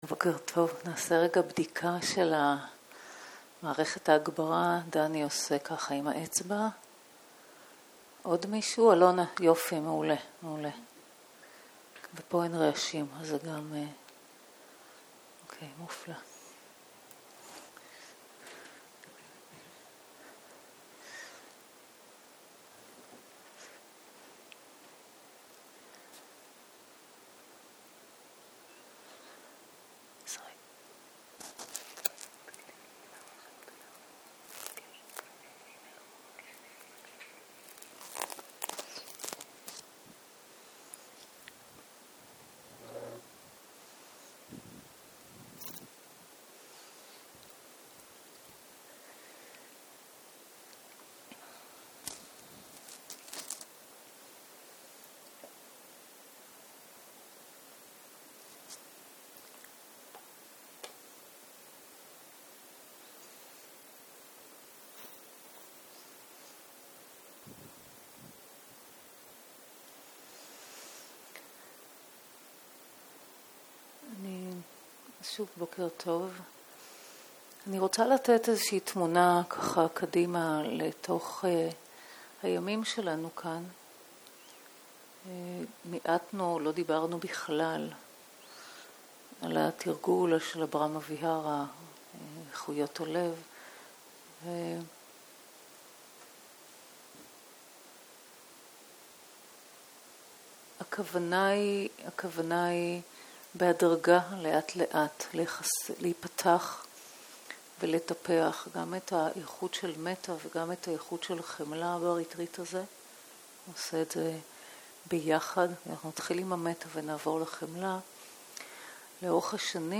בוקר - הנחיות מדיטציה
Guided meditation